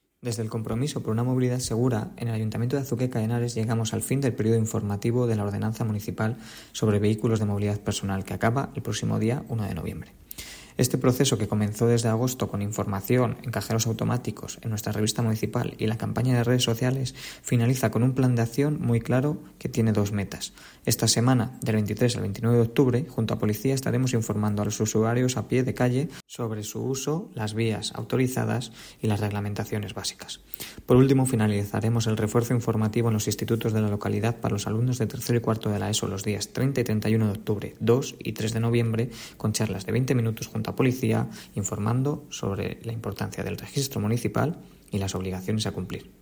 Declaraciones del concejal Rodrigo Vasco